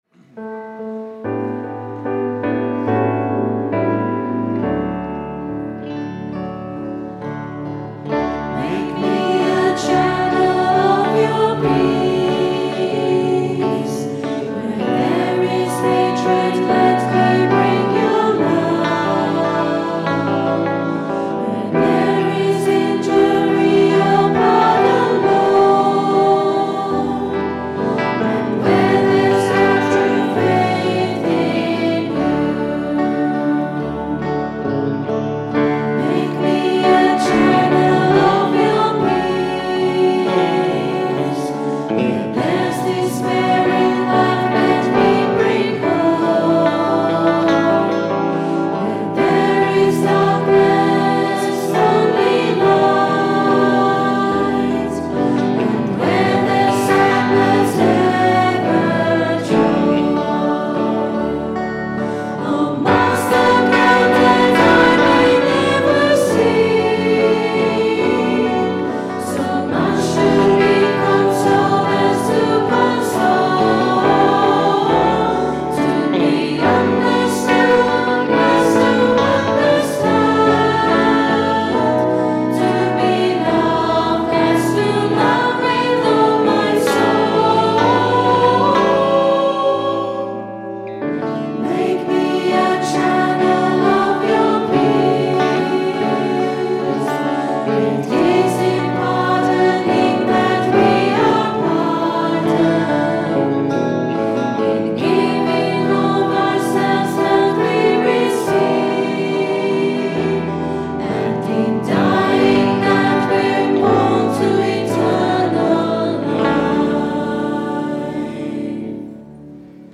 Recorded on a Zoom H4 digital stereo recorder at 10am Mass Sunday 4th July 2010.